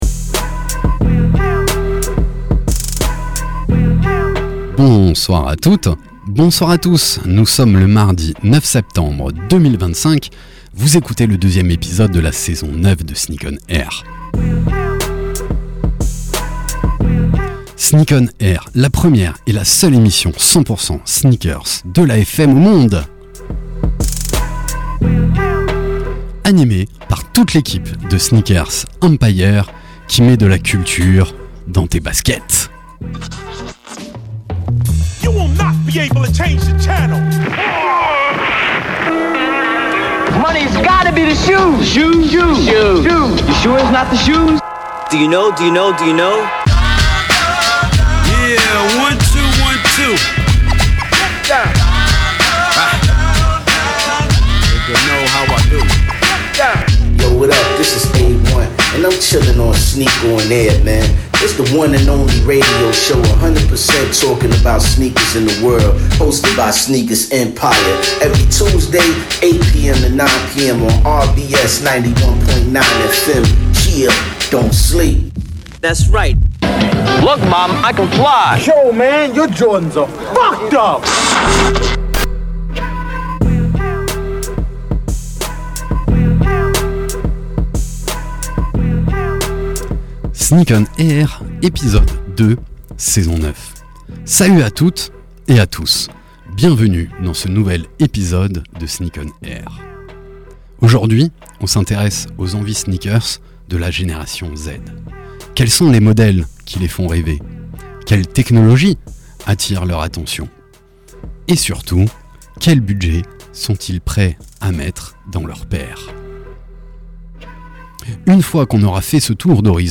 Sneak ON AIR, la première et la seule émission de radio 100% sneakers au monde !!! sur la radio RBS tous les mardis de 20h à 21h.
Pour cet épisode, nous vous proposons une heure de talk, d’actus, et de débats autour des faits marquants de l’univers de la sneaker avec tous nos chroniqueurs.